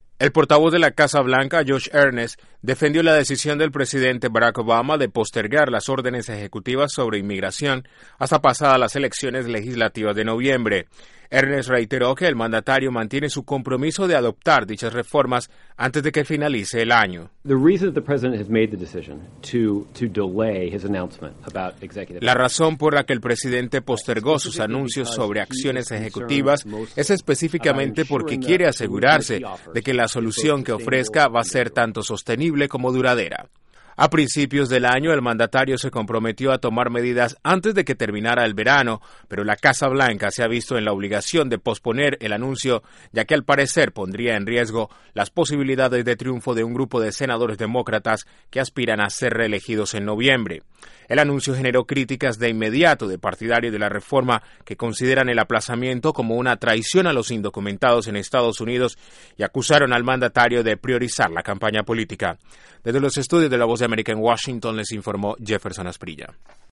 La Casa Blanca defendió la decisión del presidente Obama de postergar los decretos sobre inmigración y dice que el mandatario quiere soluciones en inmigraciones sostenibles. Desde la Voz de América en Washington informa